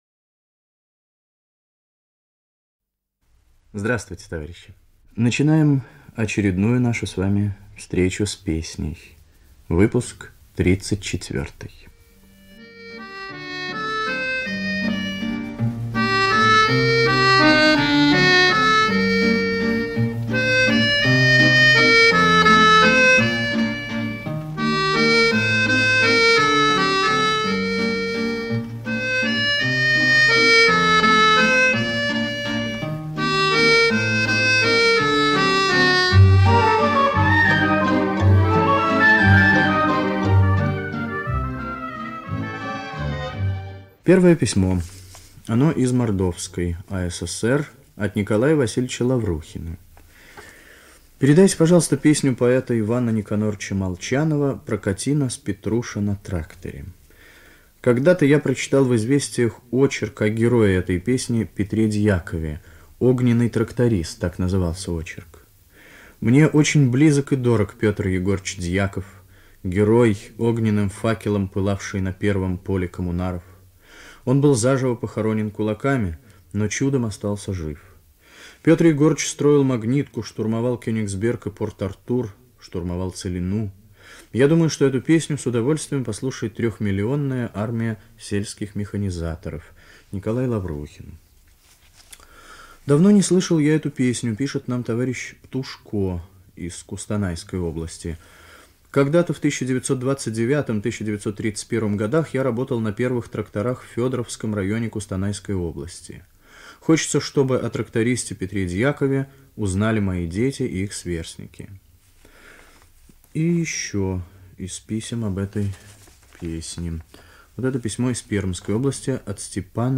в сопровождении баянов
Русская народная песня.
1. Музыкальная заставка к передаче. Исполняет оркестр;